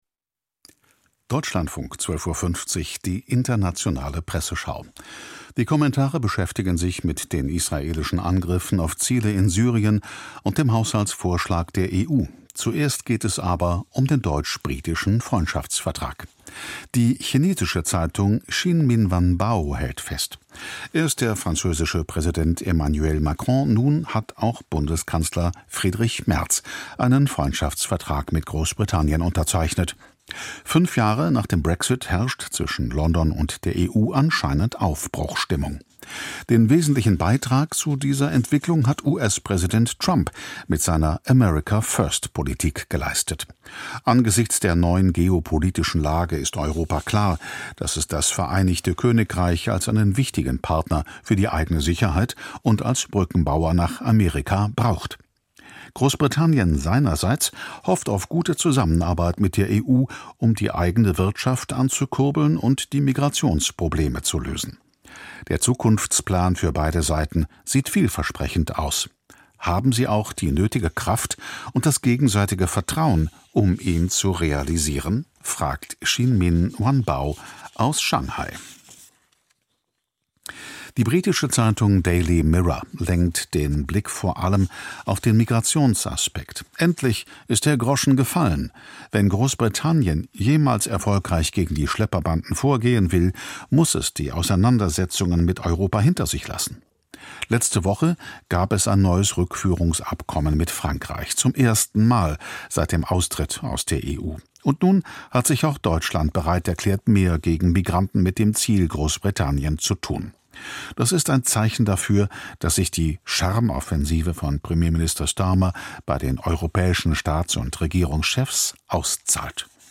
Unsere Zwischenbilanz: Wir berichten ausführlich über die wichtigsten Geschehnisse des Tages: Bundestags-Debatten, aktuelle Entwicklungen im In- und Ausland, ergänzt durch Interviews und die aktuelle Sportberichterstattung.